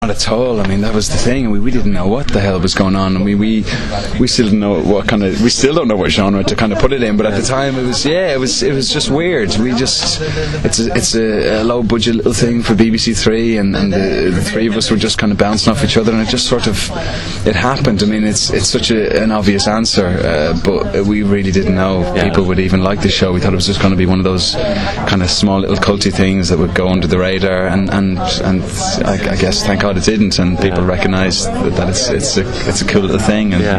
Now here are a few extracts, with audio, from the interviews with Aidan and Lenora, who is also currently starring as fashion designer Ali in BBC1 drama series Material Girl: